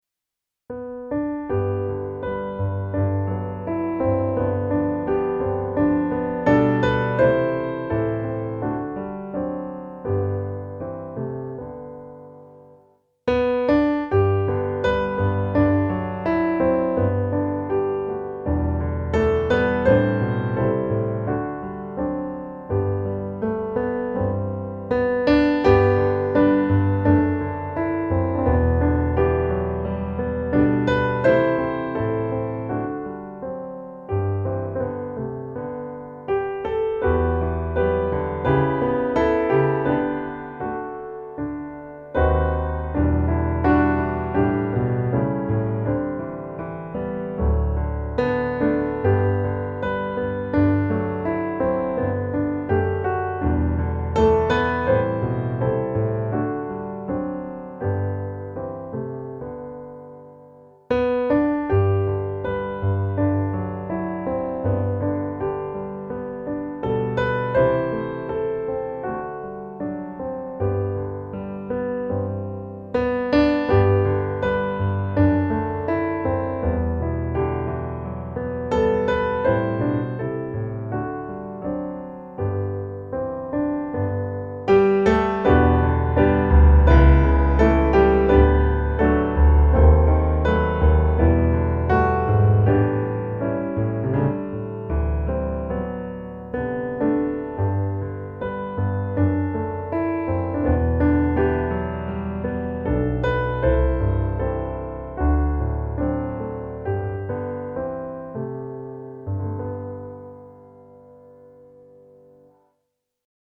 Bred dina vida vingar - musikbakgrund
Musikbakgrund Psalm